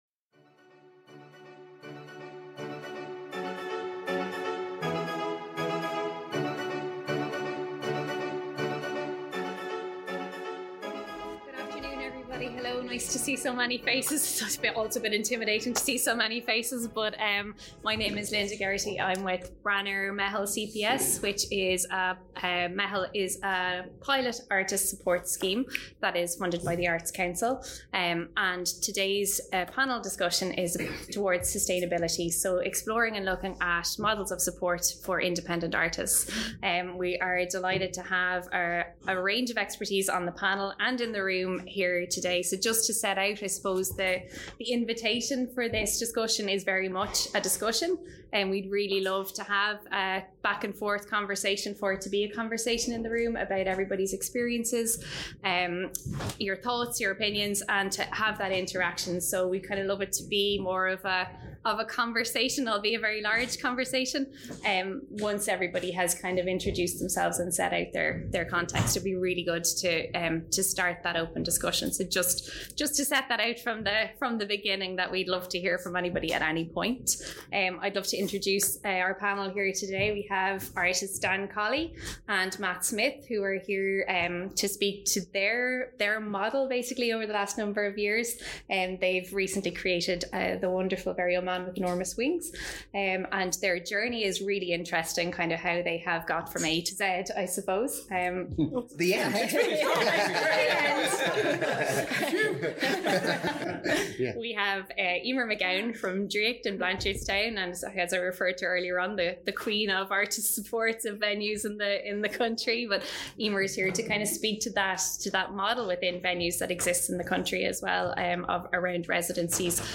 In partnership with Branar’s Meitheal Initiative, this panel discussion explored models of practice which support the sustainable development of career paths for independent artists making work for young audiences.
Recorded on October 18, 2022 during Baboró’s annual festival.